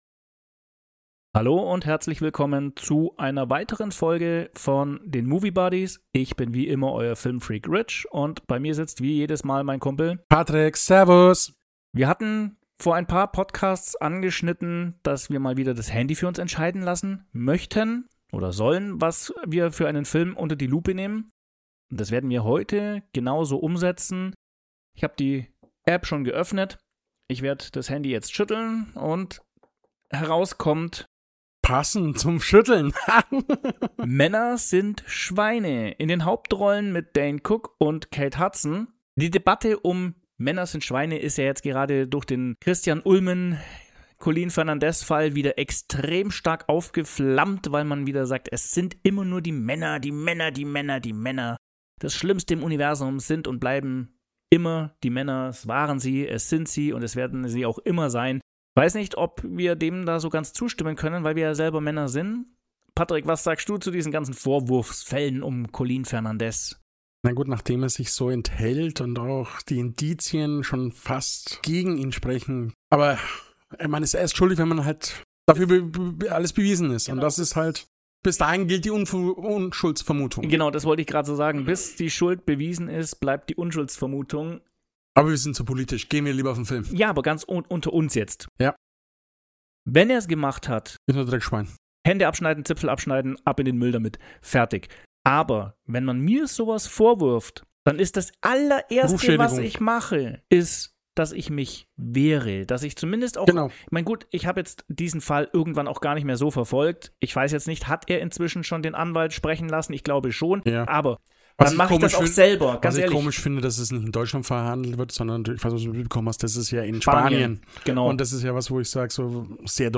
Erlebt einen irrwitzigen Podcast, der aufgrund seiner anstössigen Wortwahl garantiert nicht für jeden geeignet ist. Dennoch sprechen wir über Hintergründe, Cast und die momentane Ansicht auf Männer und Frauen.